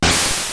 P3D-Legacy / P3D / Content / Sounds / Battle / Pokeball / break.wav
break.wav